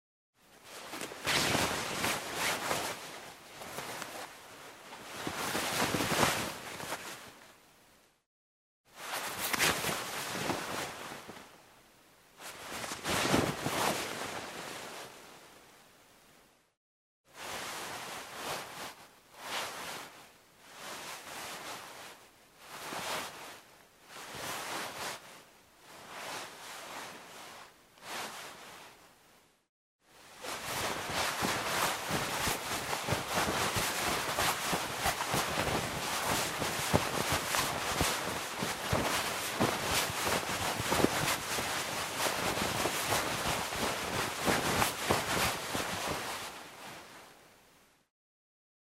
На этой странице собраны разнообразные звуки одежды: от шуршания нейлона до щелчков ремней.
Куртку надевают снимают движения